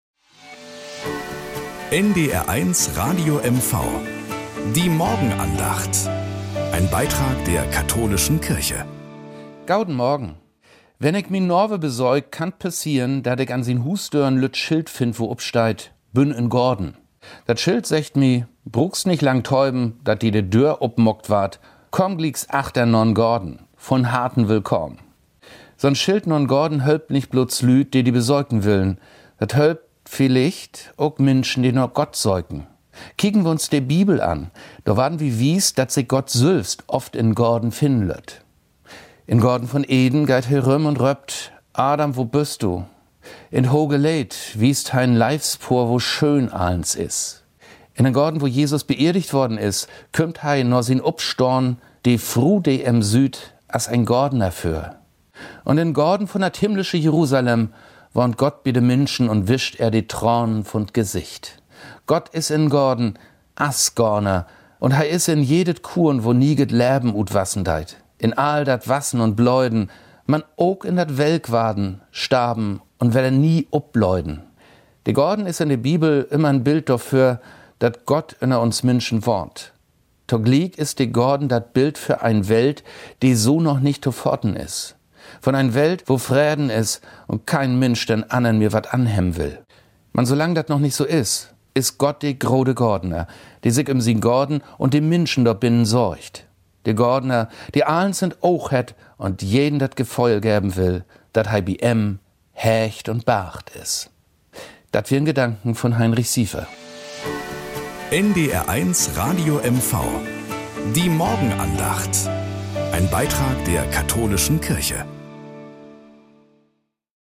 Morgenandacht auf NDR 1 Radio MV
Um 6:20 Uhr gibt es in der Sendung "Der Frühstücksclub" eine